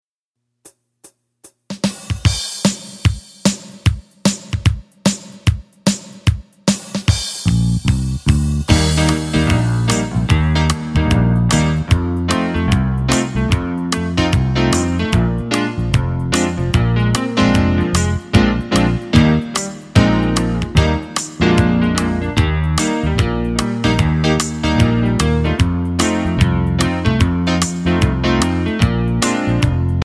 Listen to a sample of the instrumental track